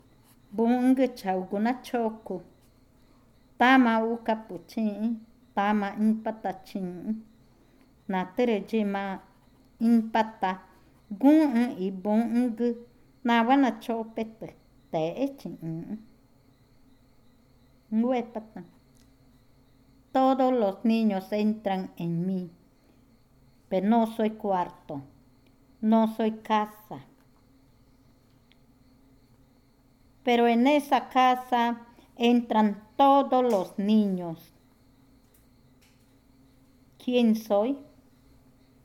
Adivinanza 10. La escuela
Cushillococha